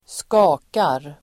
Uttal: [²sk'a:kar]